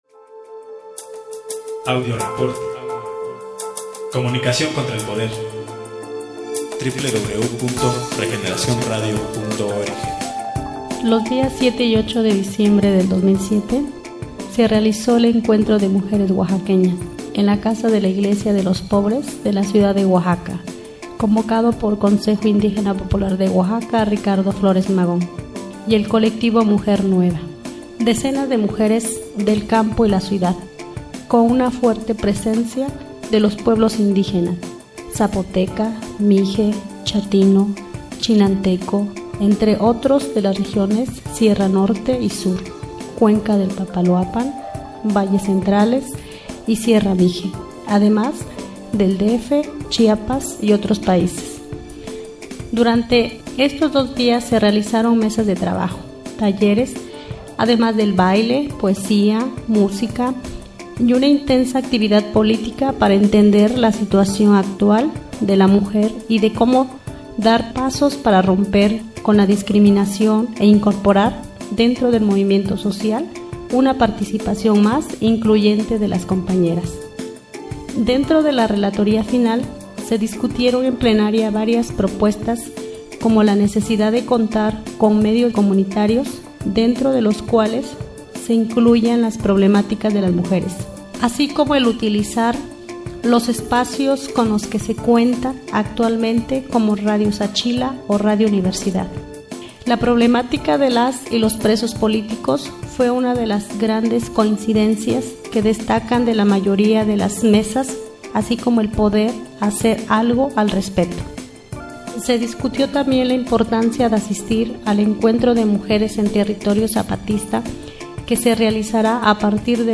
Los días 7 y 8 de Diciembre del 2007 se realizo el Encuentro de Mujeres Oaxaqueñas, en la casa de la Iglesia de los pobres de la ciudad de Oaxaca, convocado por Consejo Indígena Popular de Oaxaca Ricardo Flores Magon y el Colectivo Mujer Nueva.